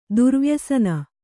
♪ durvyasana